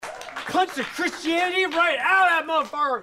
Play, download and share Punched the Christianity original sound button!!!!
punched.mp3